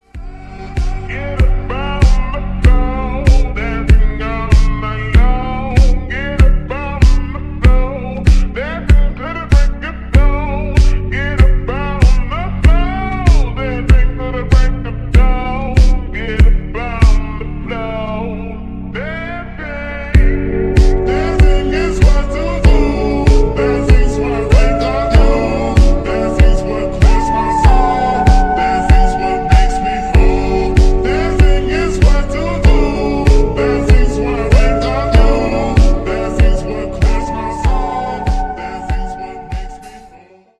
• Качество: 320, Stereo
атмосферные
спокойные
медленные
relax
reverb